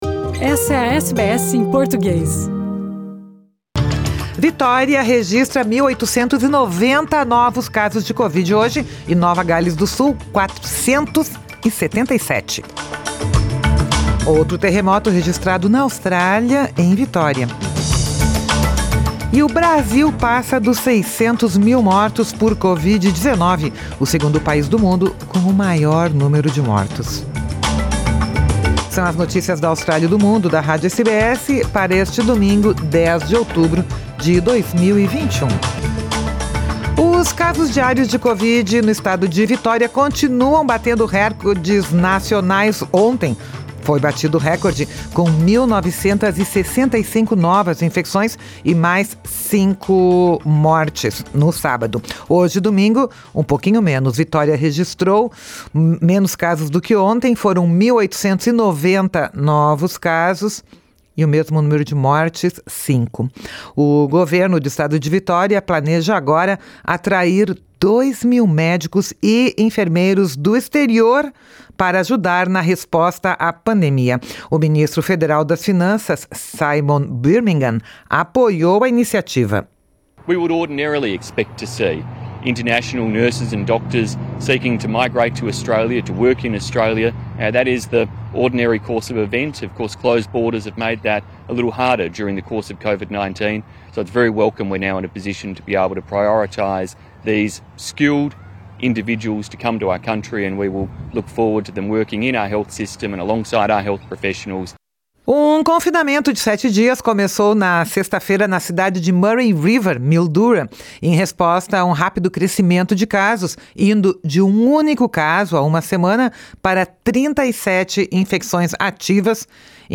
Brasil passa dos 600 mil mortos por Covid-19 - o segundo país do mundo com o maior número de mortos. São as notícias da Austrália e do Mundo da Rádio SBS para este domingo, 10 de outubro de 2021.